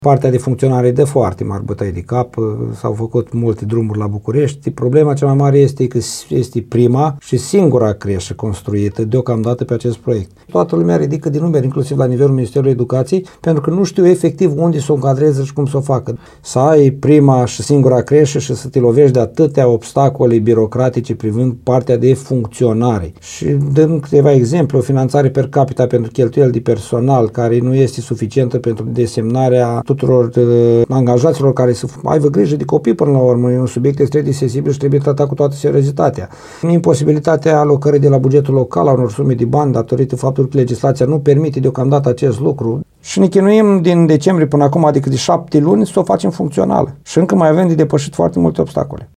Primarul CĂTĂLIN COMAN a declarat postului nostru că Ministerul Educației adoptă proceduri “extrem de birocratice” în ceea ce privește încadrarea pe posturi și salarizarea angajaților.